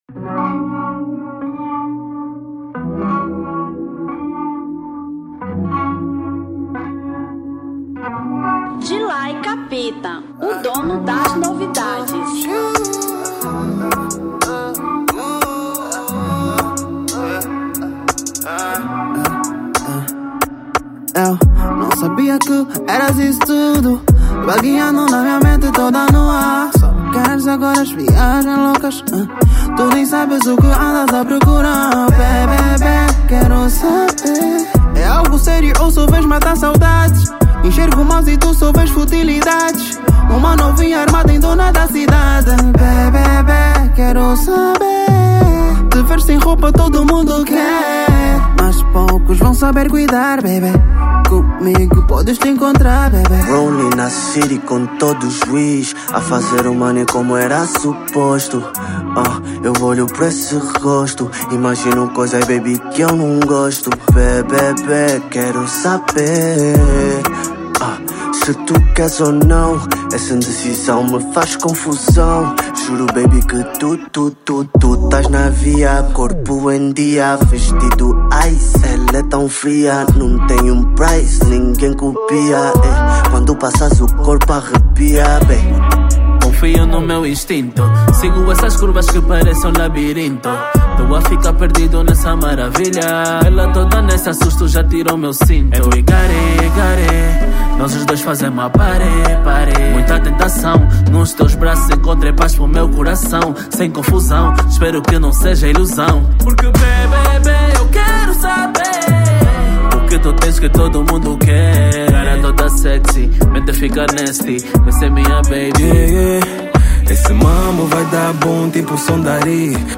Rap 2025